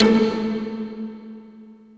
voiTTE64024voicesyn-A.wav